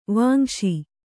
♪ vāmśi